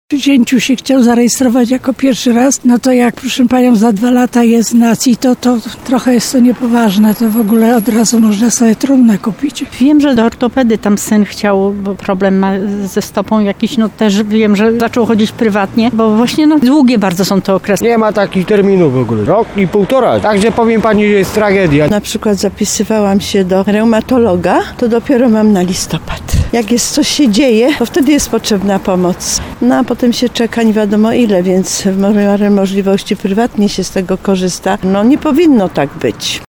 14sonda-terminy.mp3